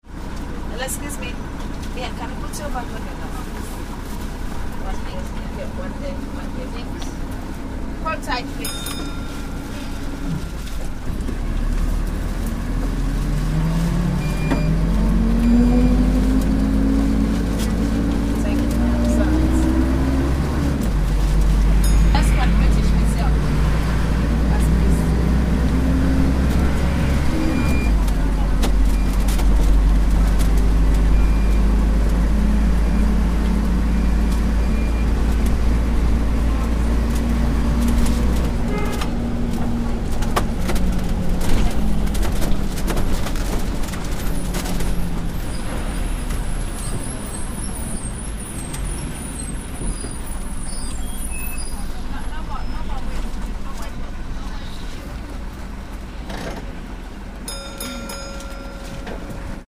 Routemaster Bus
1 min short, including the classic ding ding – hold tight
and every frequent user knew the familiar sound of the conductor calling out ‘hold tight’ followed by a ‘ding ding’ of the bell.
The Routemaster had a really unusual engine and transmission sound.
This was the seat I took to record the friendly sound of this bus, with its distinct speed-dependent tone, odd idling characteristics and typical squeal of the brakes.